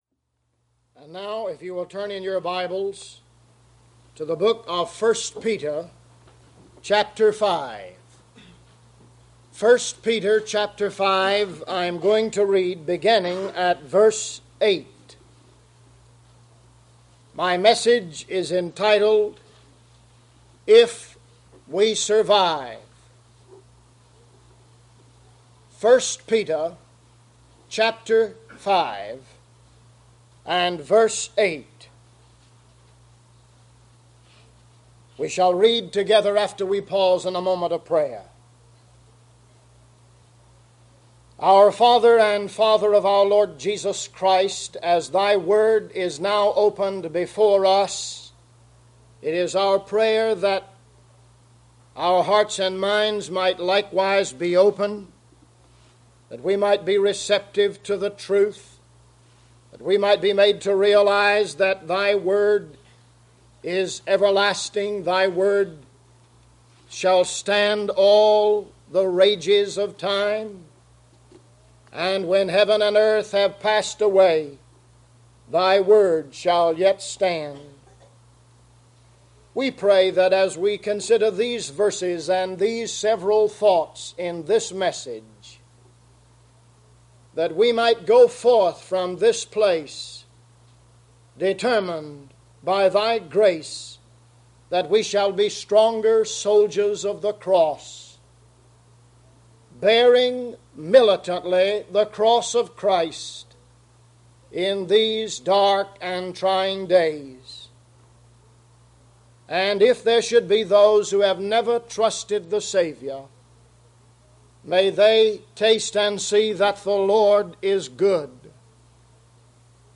Media of Worth Baptist Church of Fort Worth, Texas